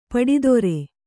♪ paḍidore